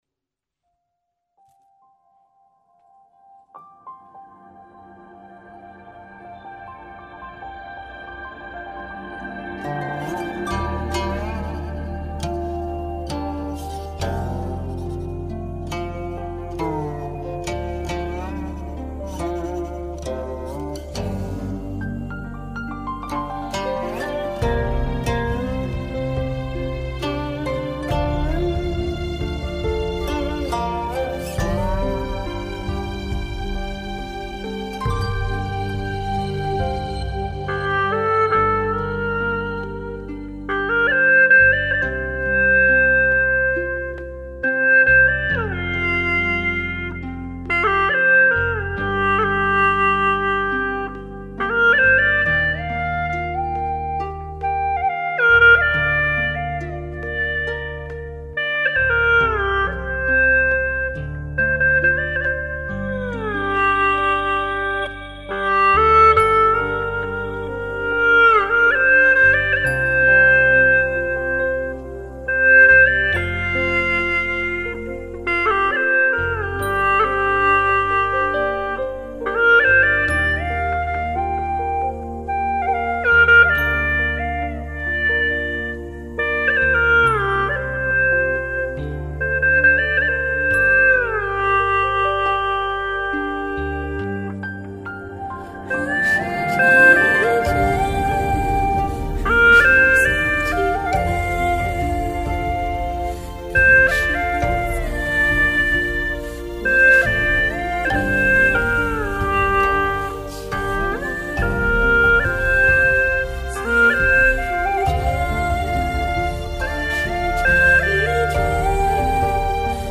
调式 : 降B 曲类 : 古风
超然平淡的丝声把人带入了宁静自在的情境！